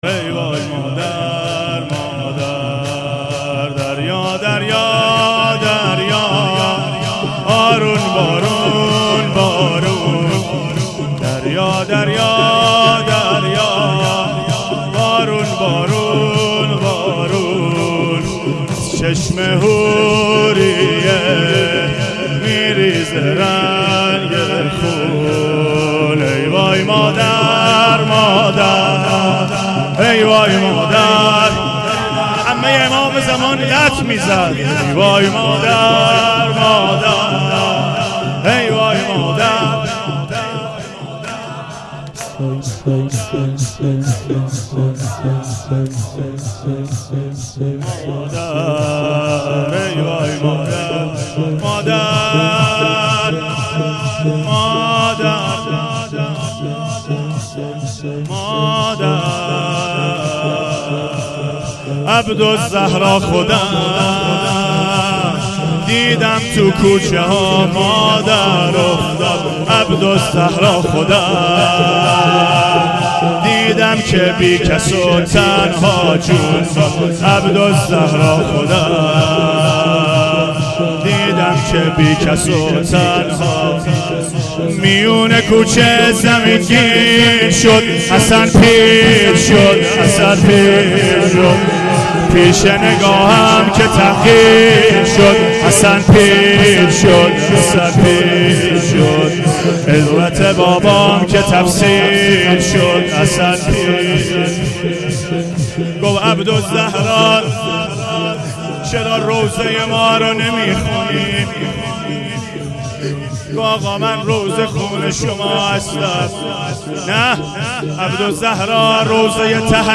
شور شب پنجم فاطمیه